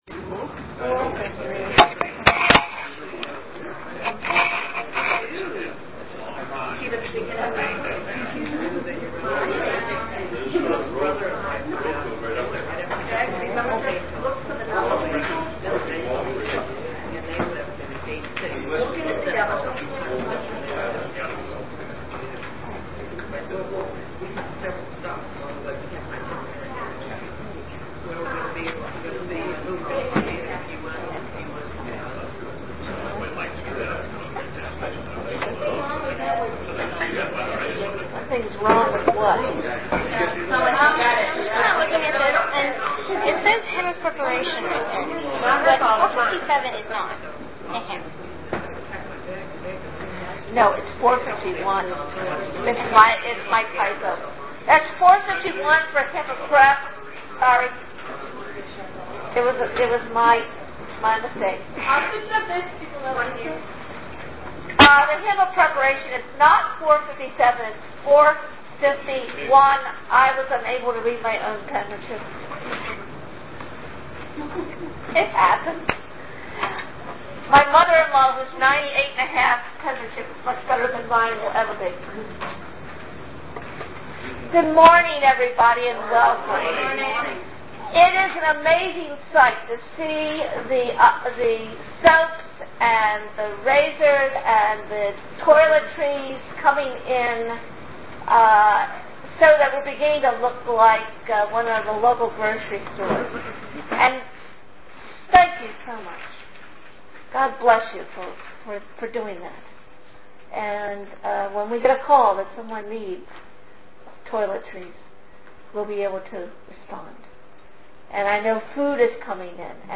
Worship – Feb. 22, 2009
Here are the details of our service on February 22, 2009.